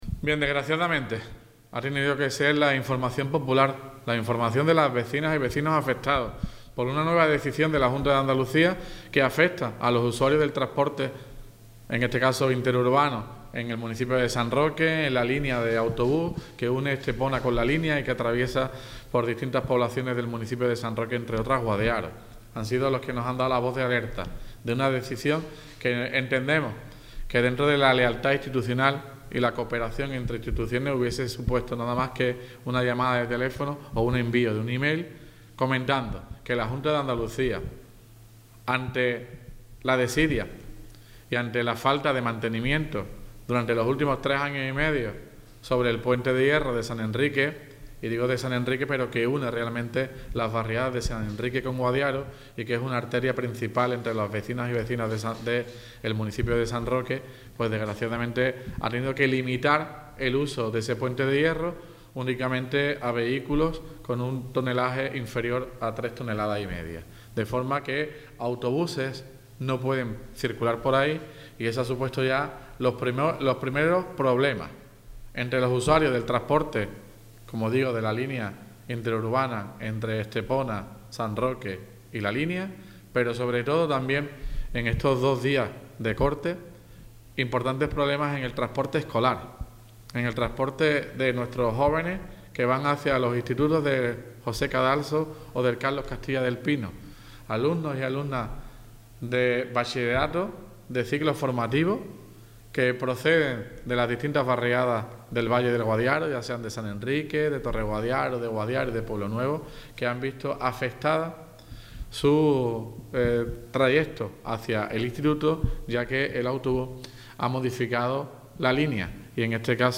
PUENTE DE HIERRO TOTAL ALCALDE.mp3